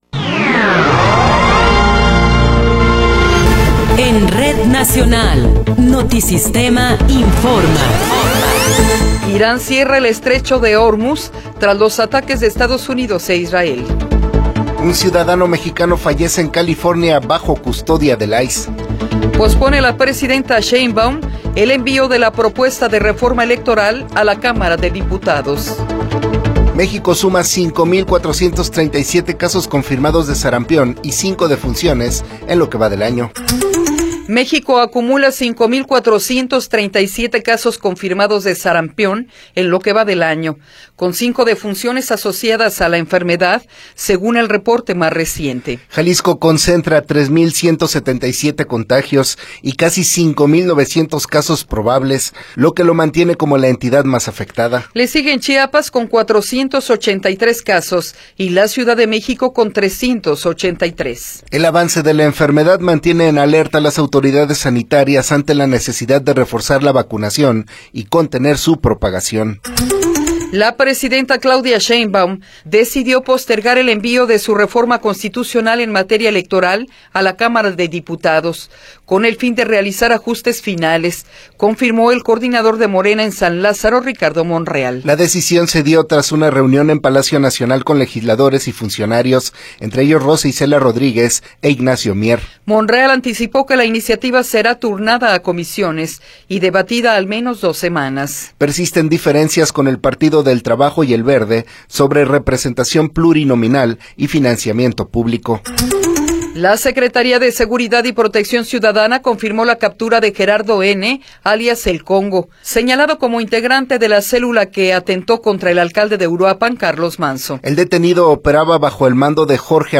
Noticiero 8 hrs. – 3 de Marzo de 2026